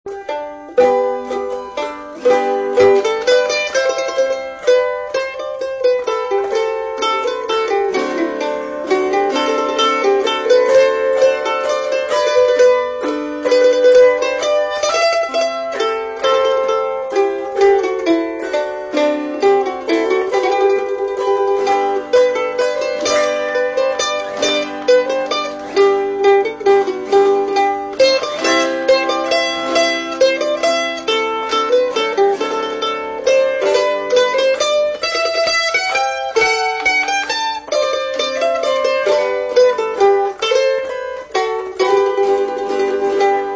Mandolin
Autoharp